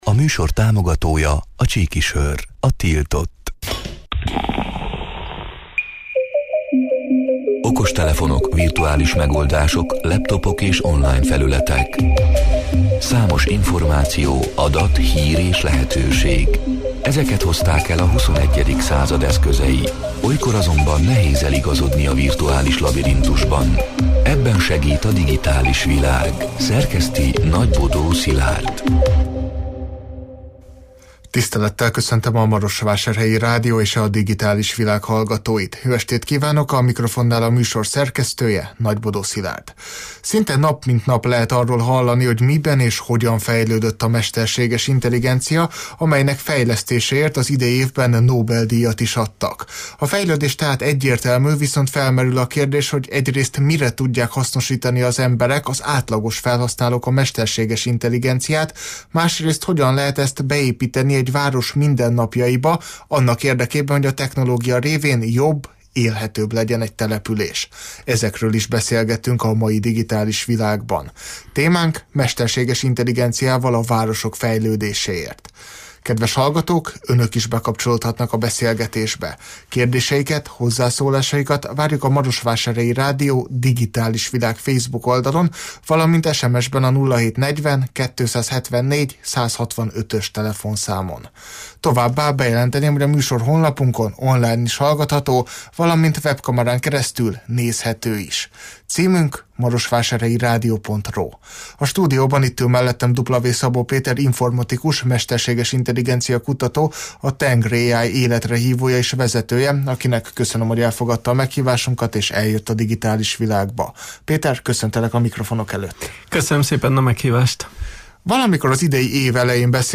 A Marosvásárhelyi Rádió Digitális Világ (elhangzott: 2024. november 12-én, kedden este nyolc órától élőben) c. műsorának hanganyaga: Szinte nap, mint nap lehet arról hallani, hogy miben és hogyan fejlődött a mesterséges intelligencia, amelynek fejlesztéséért az idei évben Nobel-díjat is adtak.